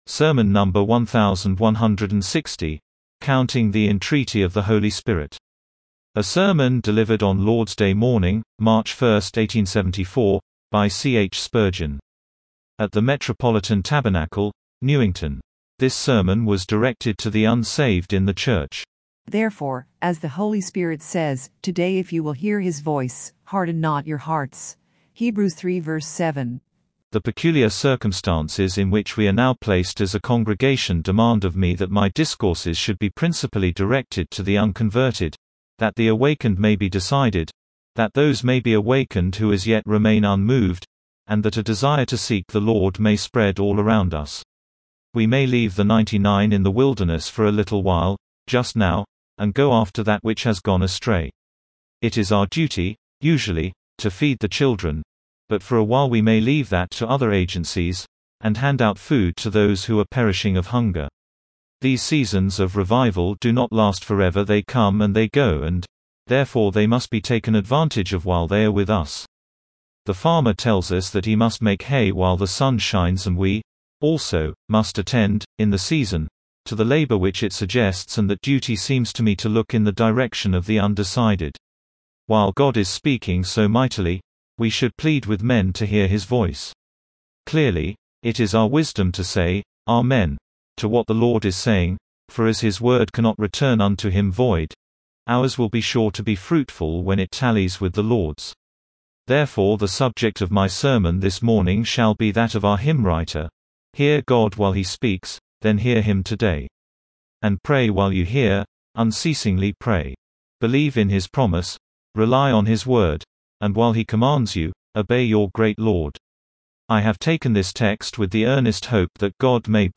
Sermon #1,160, COUNTING THE ENTREATY OF THE HOLY SPIRIT